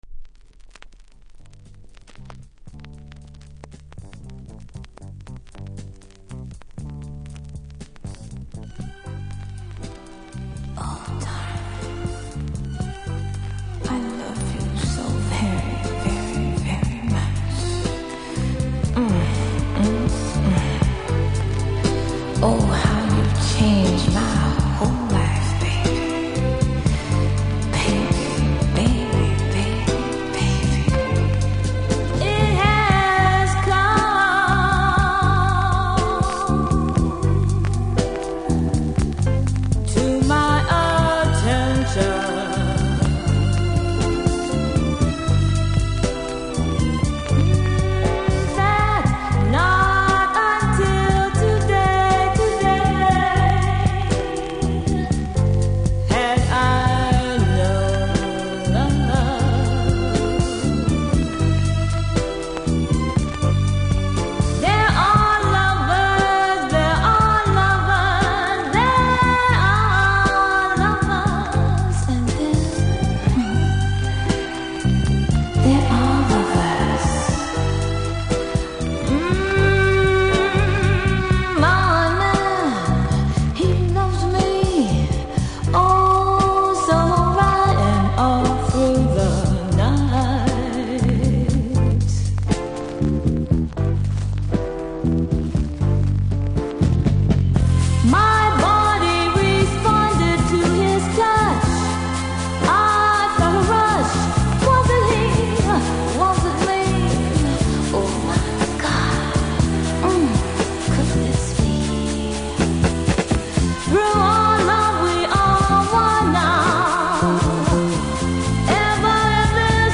1975, Jamaica Blank, Vinyl
CONDITION：VG ( WOL )